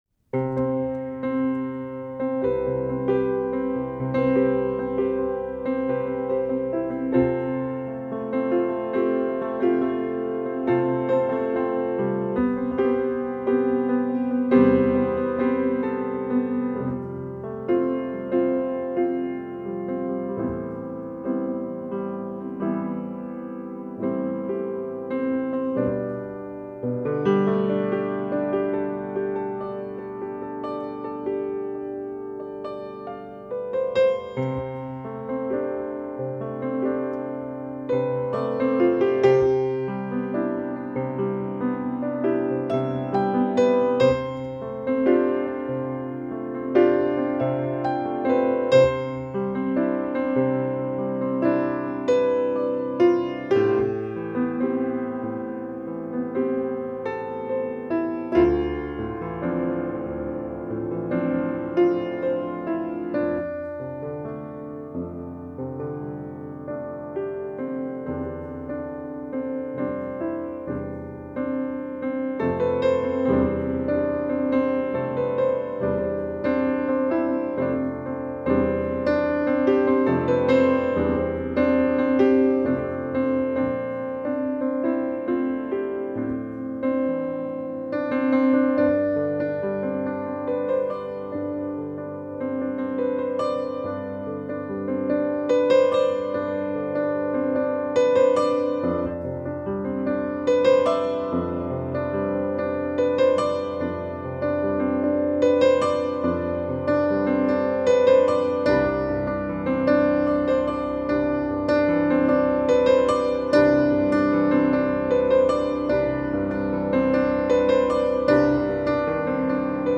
Piano Improvisation recorded at CSL Fremont, early